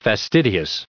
added pronounciation and merriam webster audio
336_fastidious.ogg